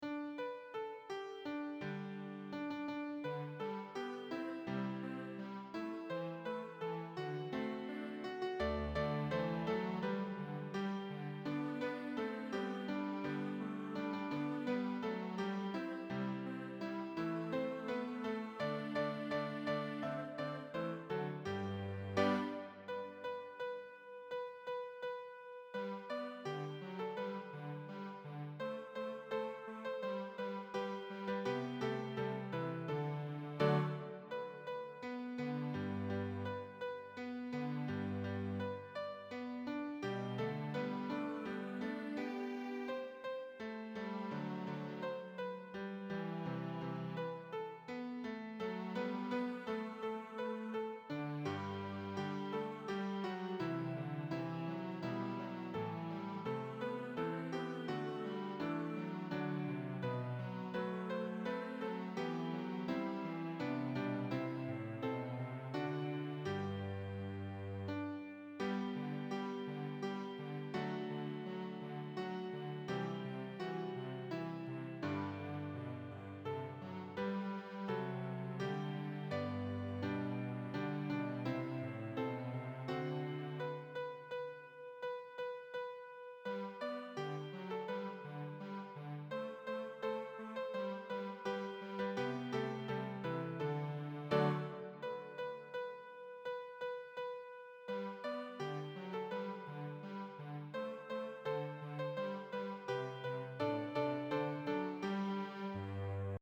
Sorry about the MIDI-quality mp3 files.
Cello & piano